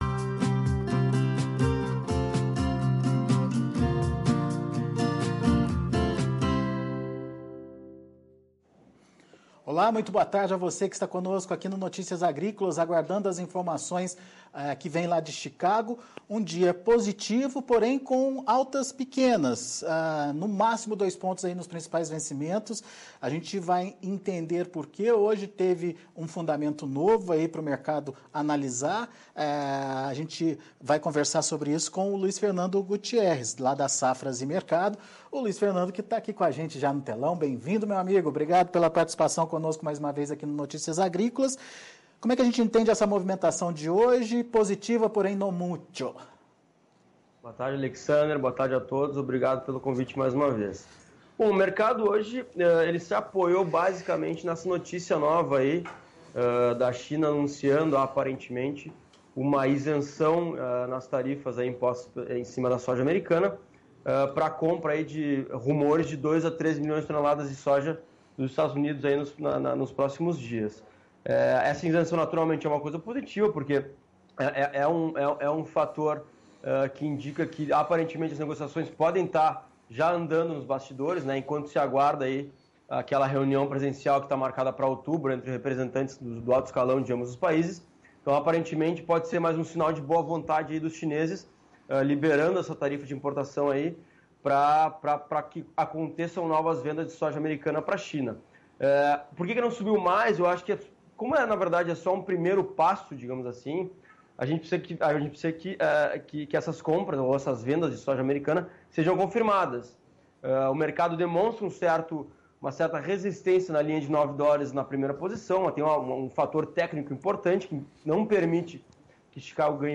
Fechamento de Mercado da Soja - Entrevista